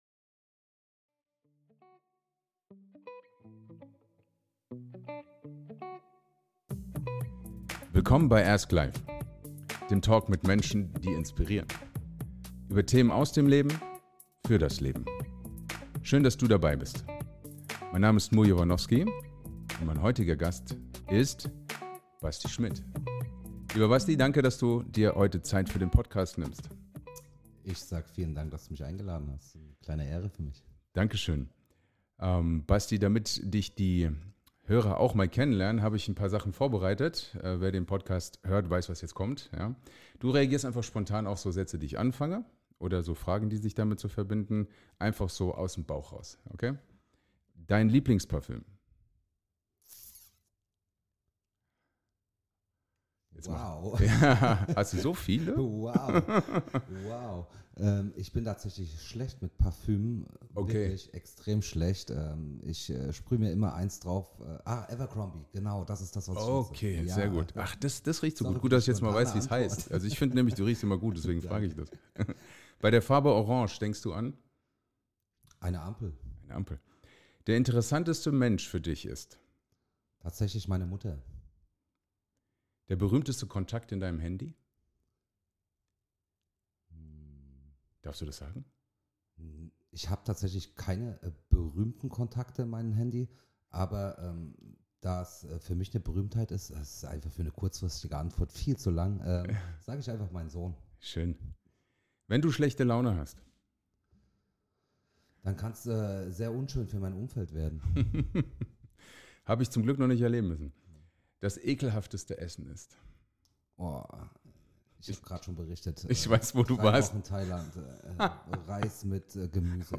Play Rate Listened List Bookmark Get this podcast via API From The Podcast Das ist ASK LIFE: Ein Talk mit Menschen, die inspirieren.